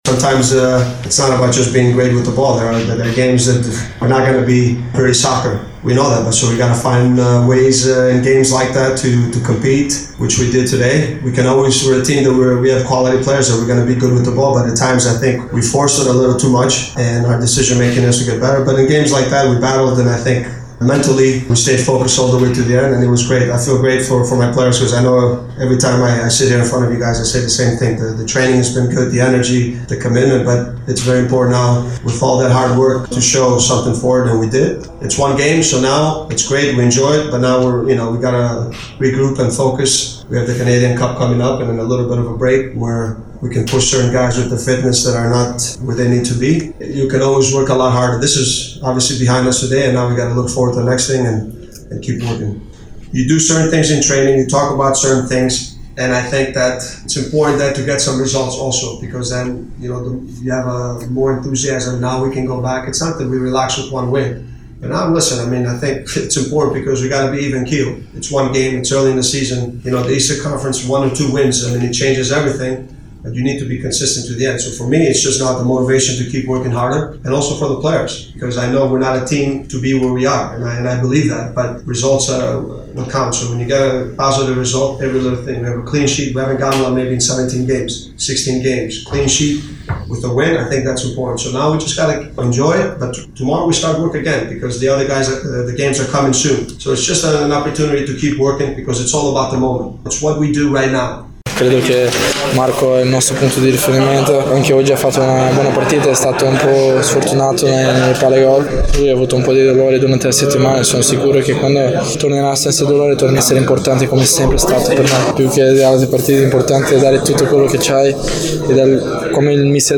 Les entrevues